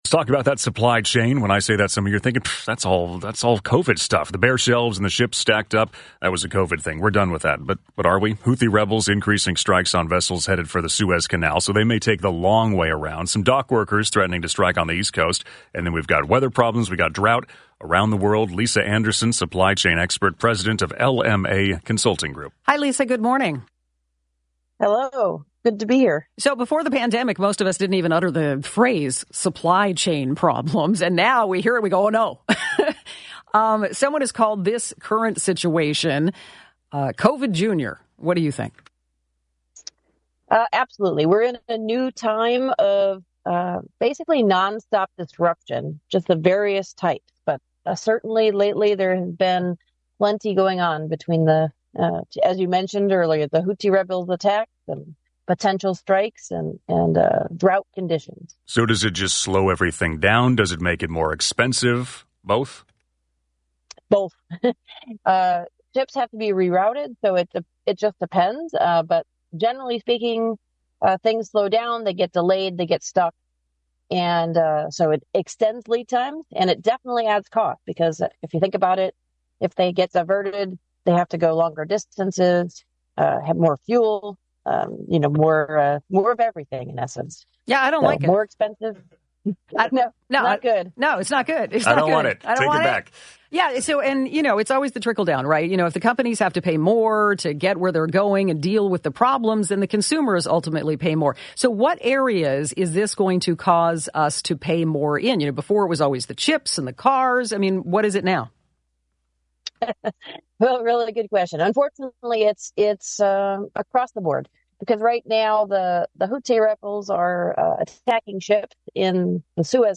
Listen to our recent interview on KNX radio about these precarious set of circumstances and the effect on consumers.